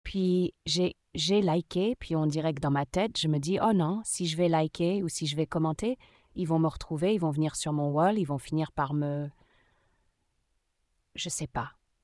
Entretien 1 avec participante 2, 17 mai 2024 (extrait audio 2)
Extrait audio d'un entretien de recherche avec voix modifiée pour conserver l'anonymat.